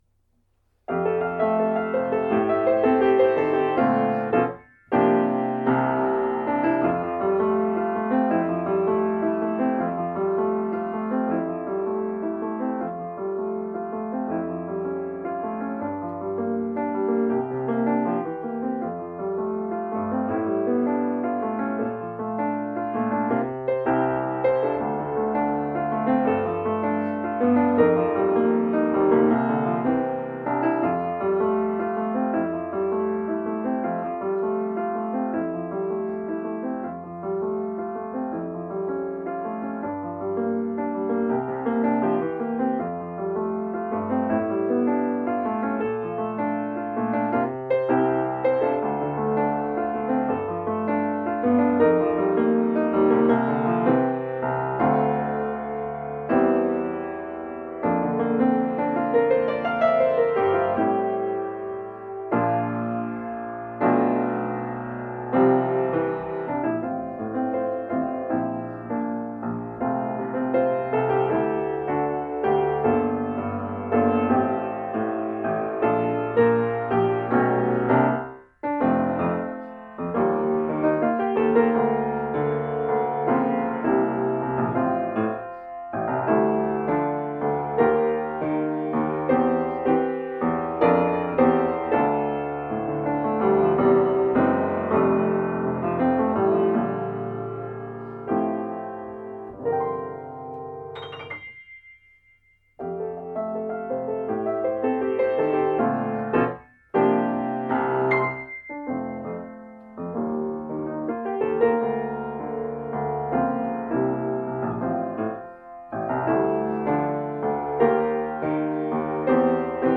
...I've written a fun, upbeat song to celebrate performing during this frustrating time of not being allowed to do proper concerts.
Piano audio
piano.mp3